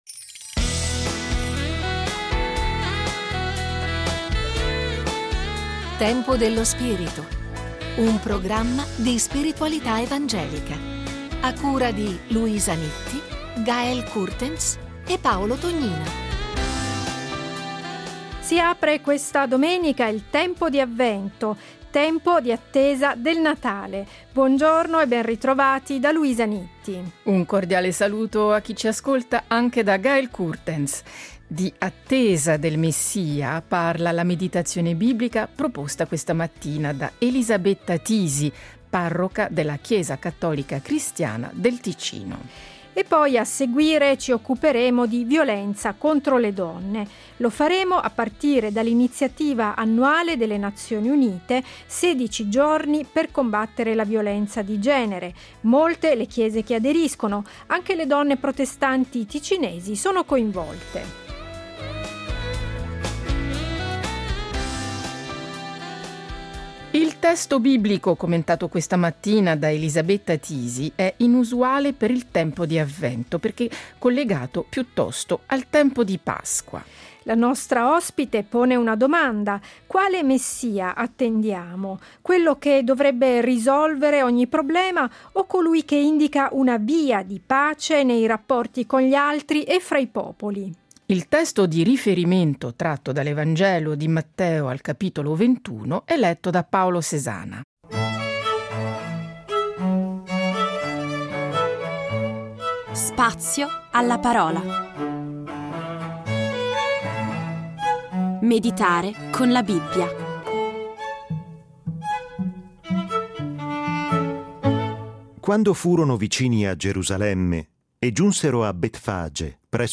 Scopri la serie Tempo dello spirito Settimanale di spiritualità evangelica.